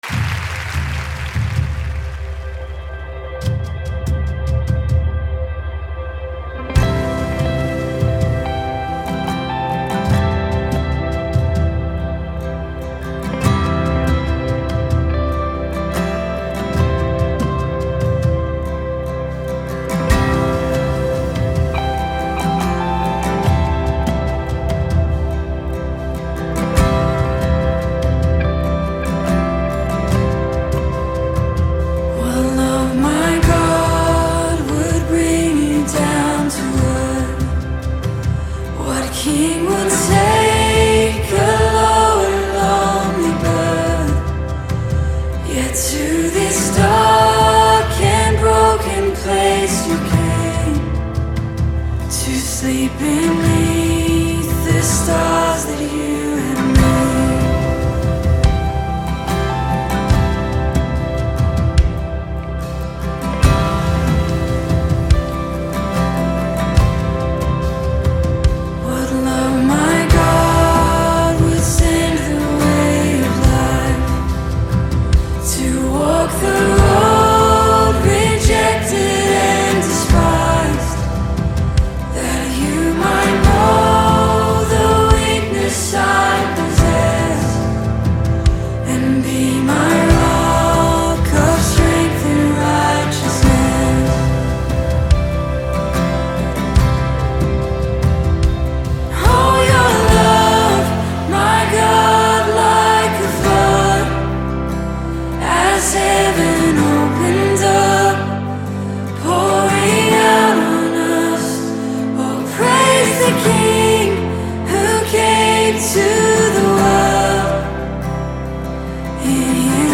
赞美诗